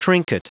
Prononciation du mot trinket en anglais (fichier audio)
Prononciation du mot : trinket